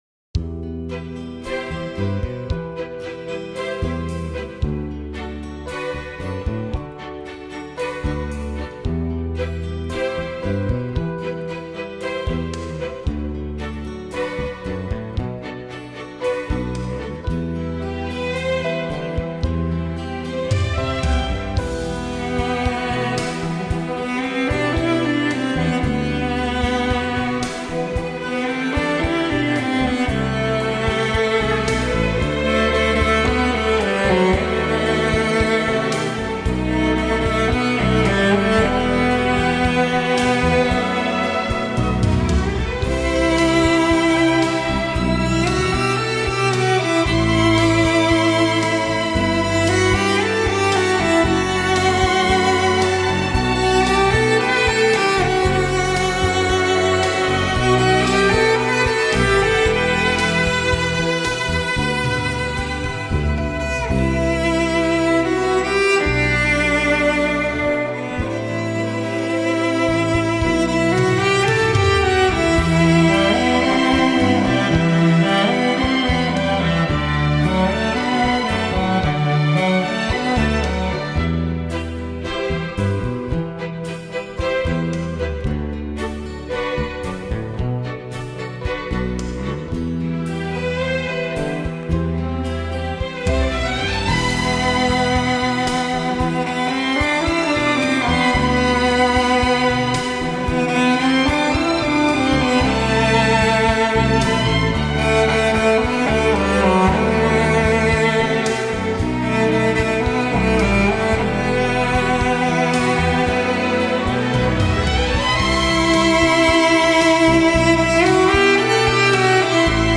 [22/1/2010]纯音乐欣赏：-----------《小船之歌》 激动社区，陪你一起慢慢变老！